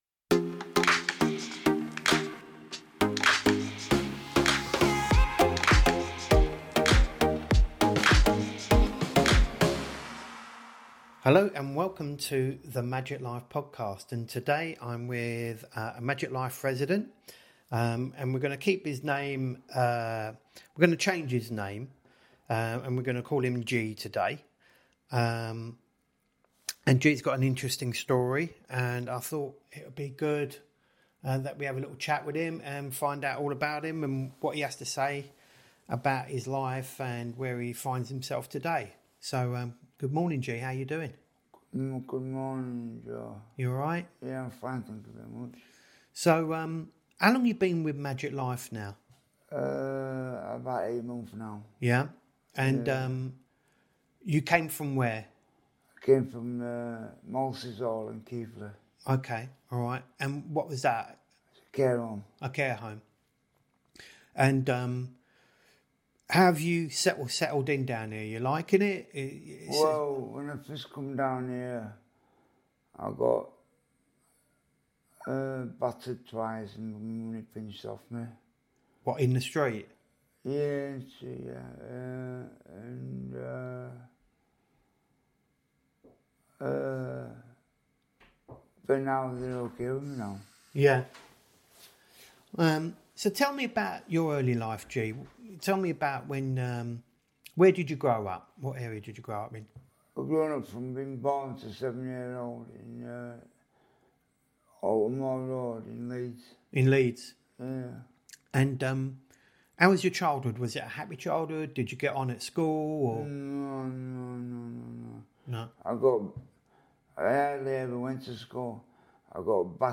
A powerful mental health recovery story after prison, trauma, and addiction. An honest conversation about survival, structure, and second chances.